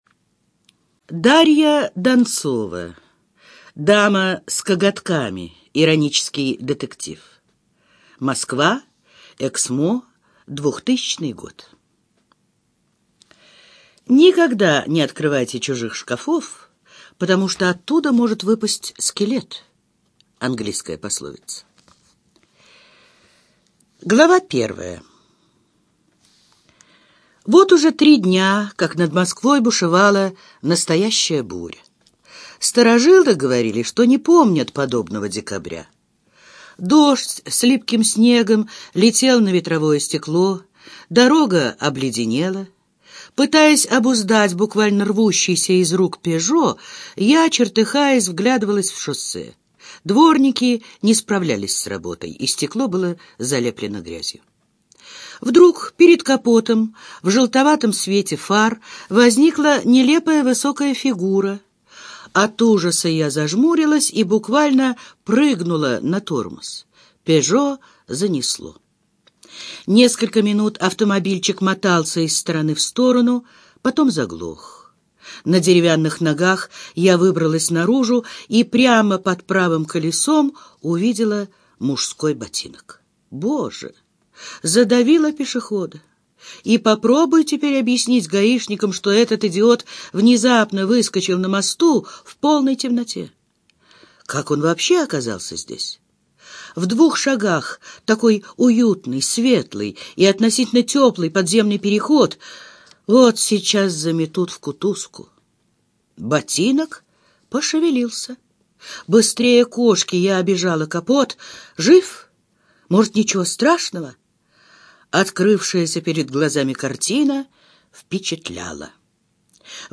Аудиокнига Дама с коготками - купить, скачать и слушать онлайн | КнигоПоиск